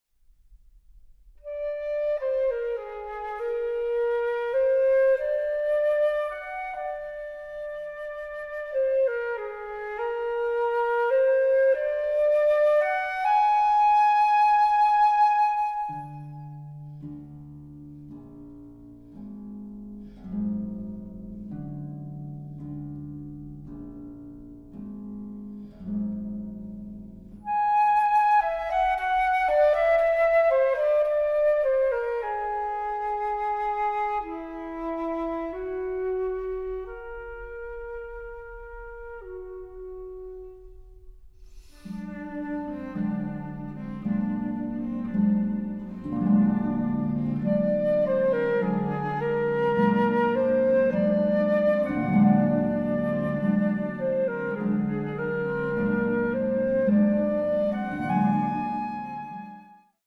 Flöte
Violoncello
Harfe